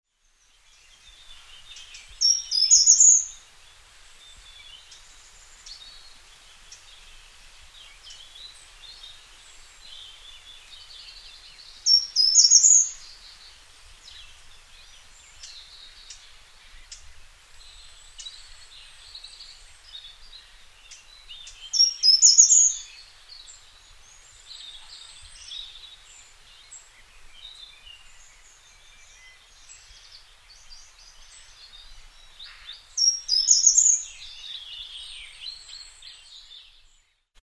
Short-toed Treecreeper  Certhia brachydactyla
Sonogram of Short-toed Treecreeper song
Parque Natural de Los Alcornocales, Andalucia, Spain  36° 09' 42.4" N   05° 34' 55.0" W  1 Apr 2012, 1210h
Territorial song from cork oak/holm oak woodland.